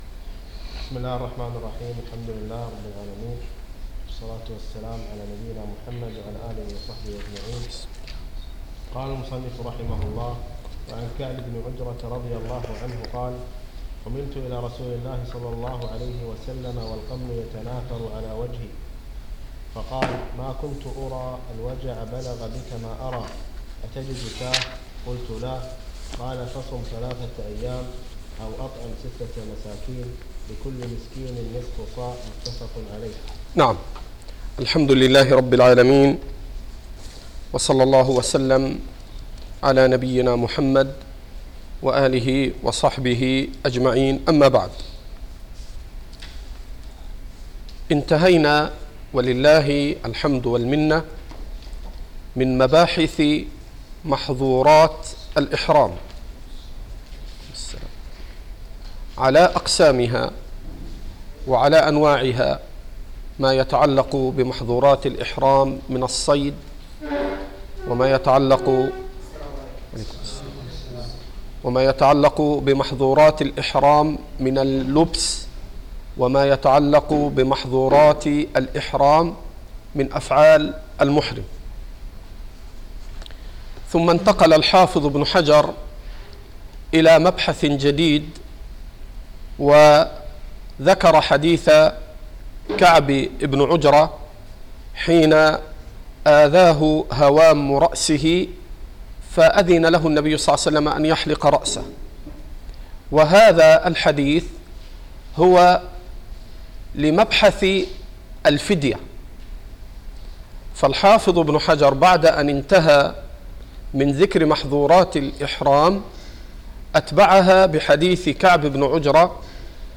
الدرس العاشر - شرح كتاب الحج من بلوغ المرام